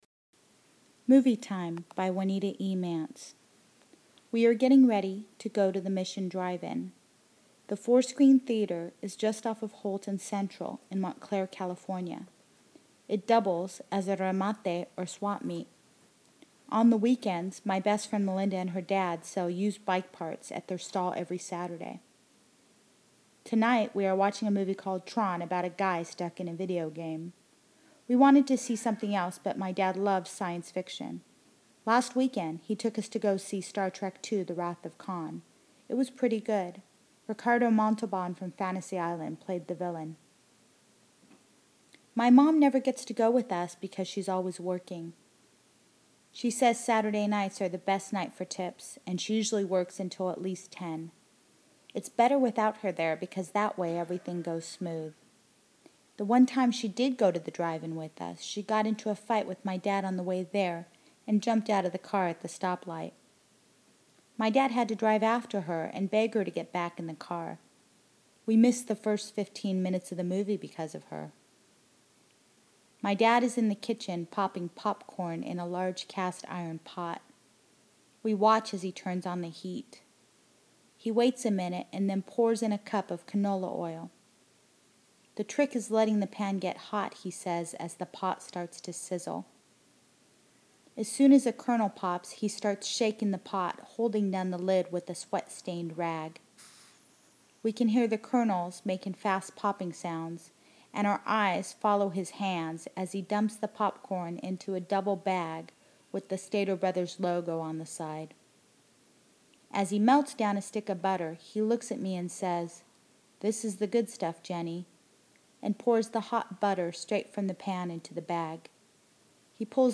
Creative Nonfiction